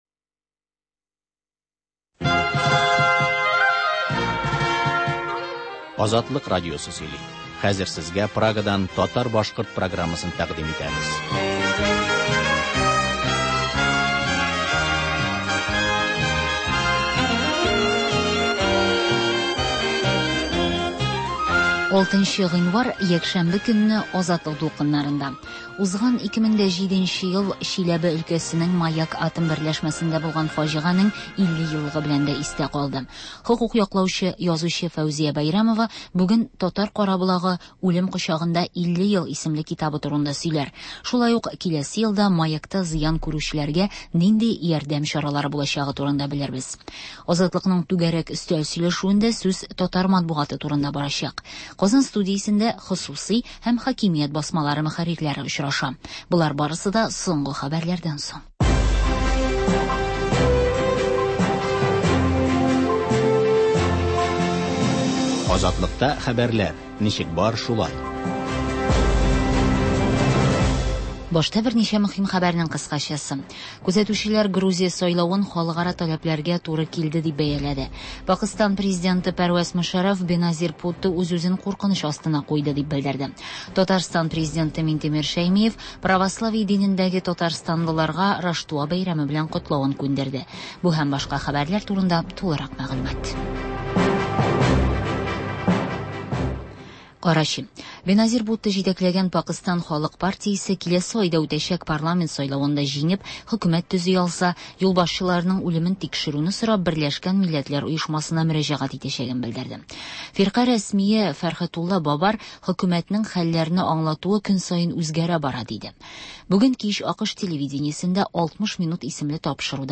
Азатлык радиосы бар атнага күз сала - соңгы хәбәрләр - Башкортстаннан атналык күзәтү - түгәрәк өстәл артында сөйләшү